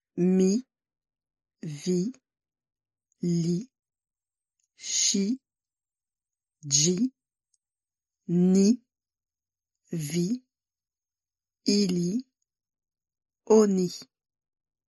Écouter comment prononcer les pronoms :